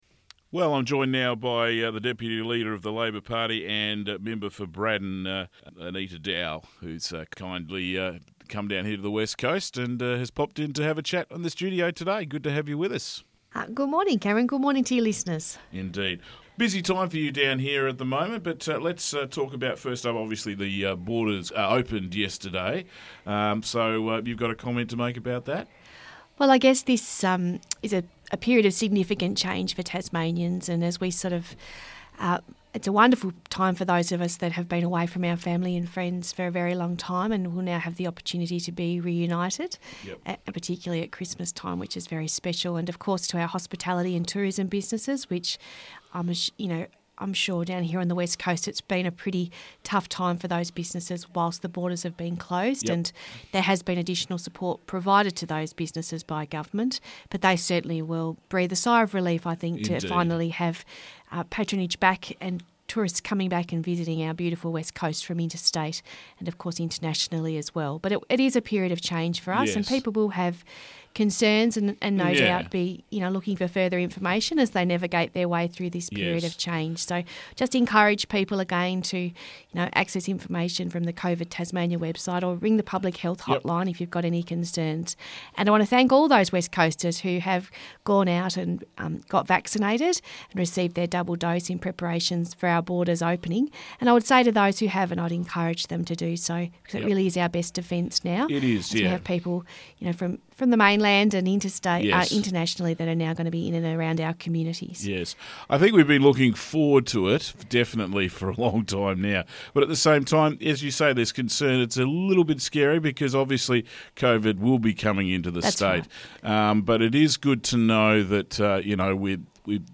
Interview with Deputy Leader of the Tasmanian Labour party Anita Dow